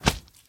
slime_big2.ogg